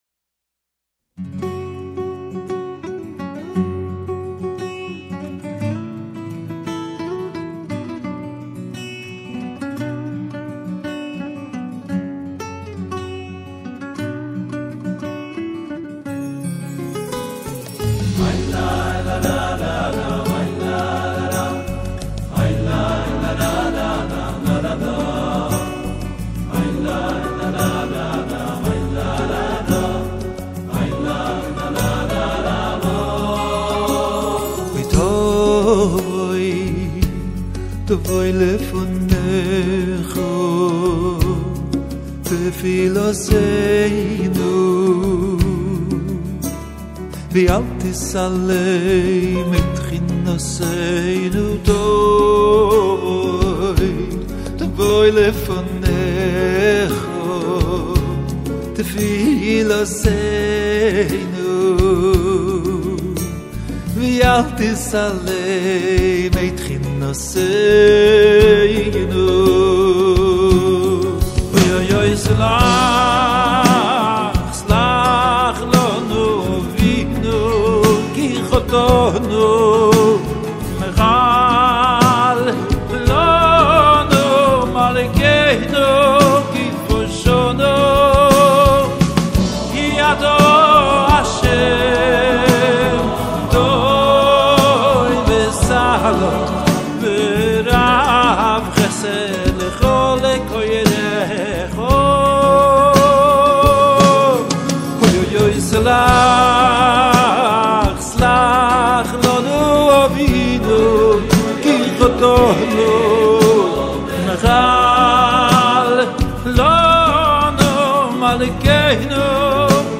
בדואט מרגש!